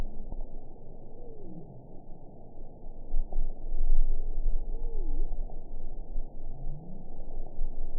event 922131 date 12/26/24 time 23:34:57 GMT (5 months, 3 weeks ago) score 5.04 location TSS-AB06 detected by nrw target species NRW annotations +NRW Spectrogram: Frequency (kHz) vs. Time (s) audio not available .wav